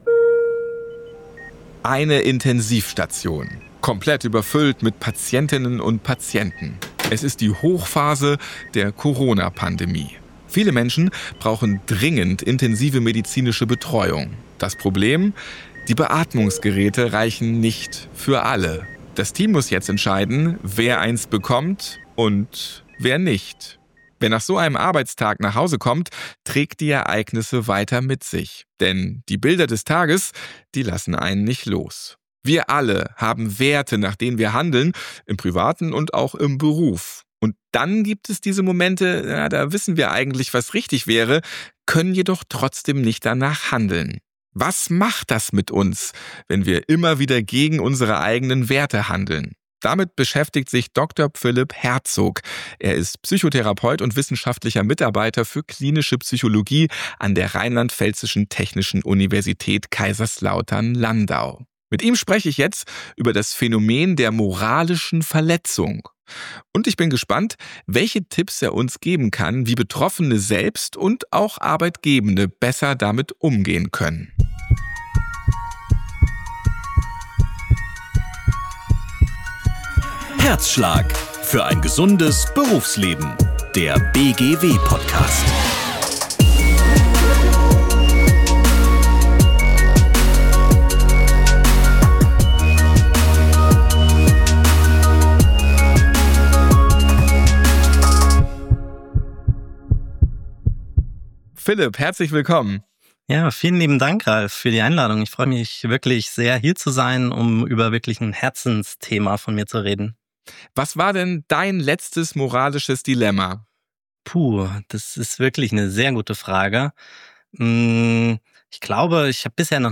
Die beiden sprechen darüber, wie sich moralische Verletzungen von Burnout oder einer posttraumatischen Belastungsstörung unterscheiden, welche Berufsgruppen besonders gefährdet sind und warum strukturelle Bedingungen dabei oft eine entscheidende Rolle spielen.